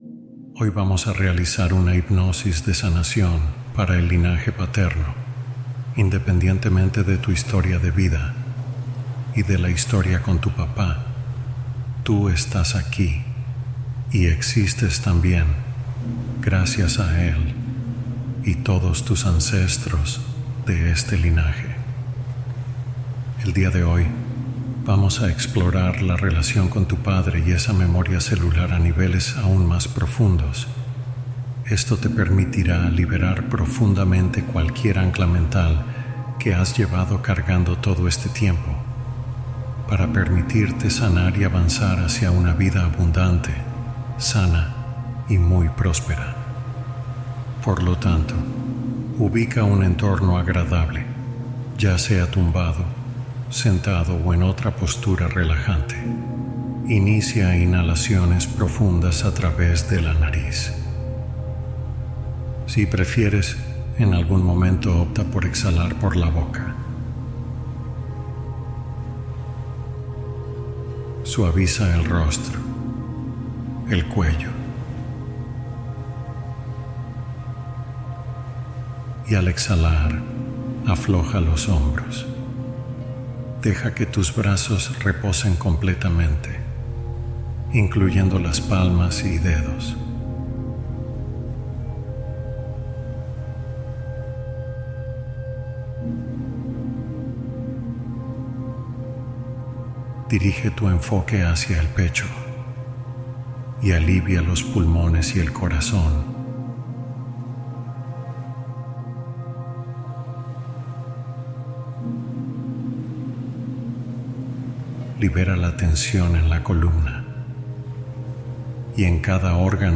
Explorar la relación paterna: hipnosis y afirmaciones conscientes